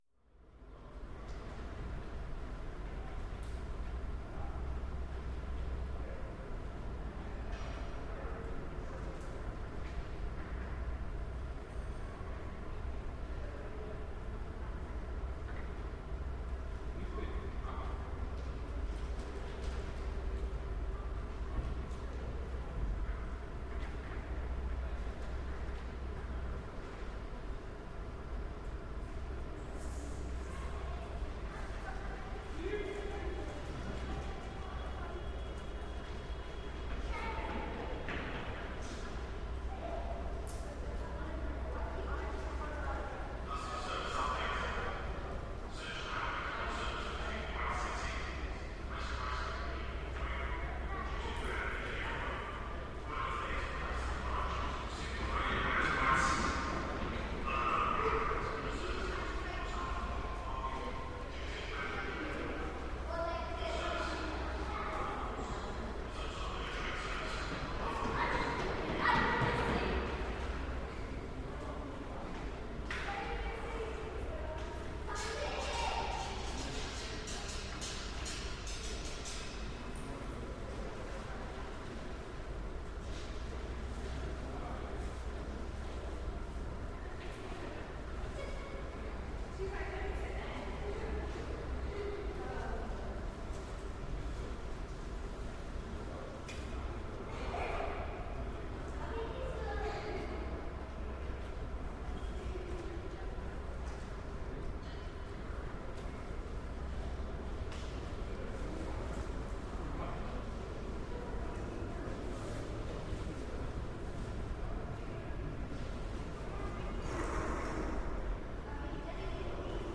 Field recording from the London Underground by London Sound Survey.